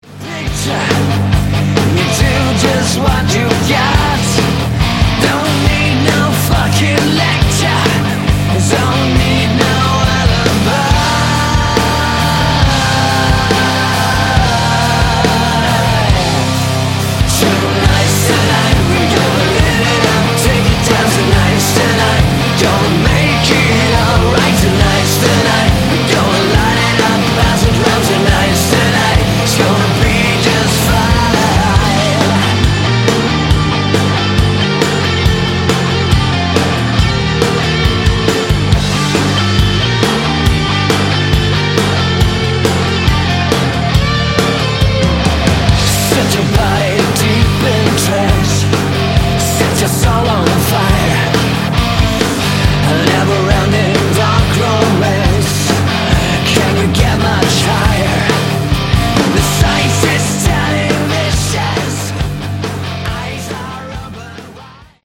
Category: Sleaze/Hard Rock
guitar
bass
drums
vocals